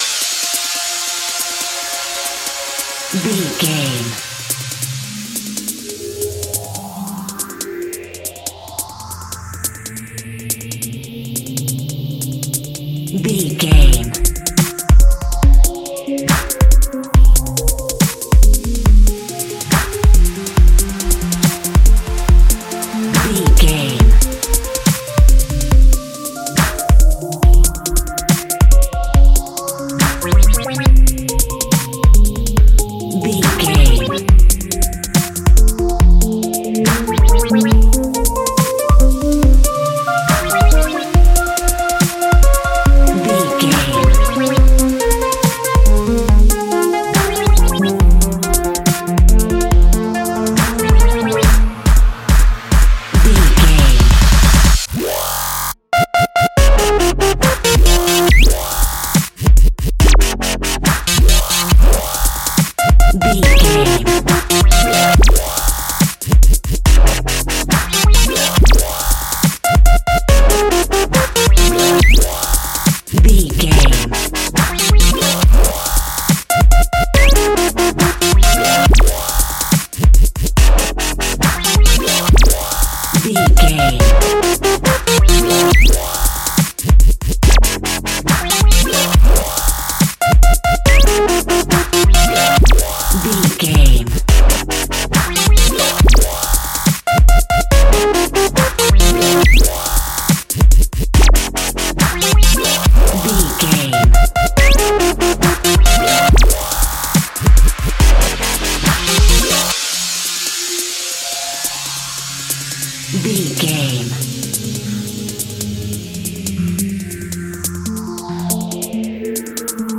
Epic / Action
Fast paced
Aeolian/Minor
aggressive
powerful
dark
driving
energetic
intense
drum machine
synthesiser
futuristic
breakbeat
instrumentals
synth leads
synth bass